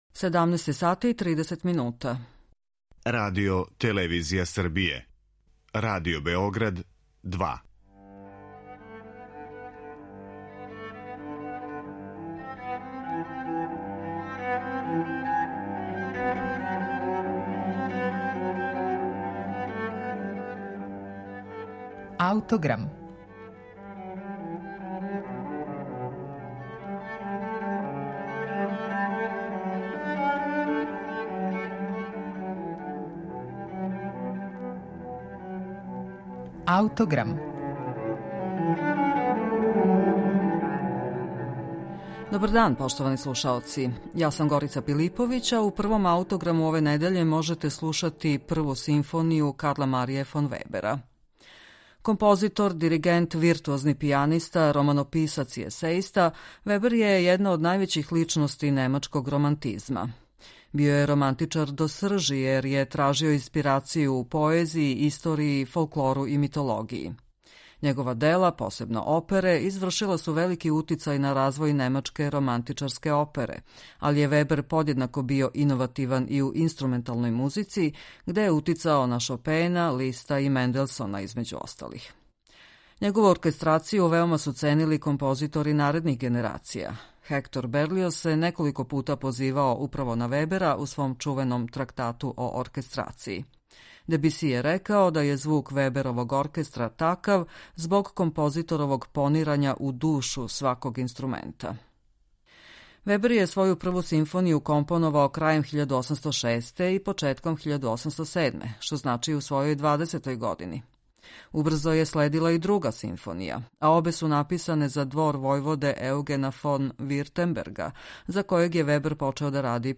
Због тога у партитури нема деонице кларинета, инструмента који је Вебер иначе врло радо и сврсисходно користио.